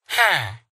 yes1.ogg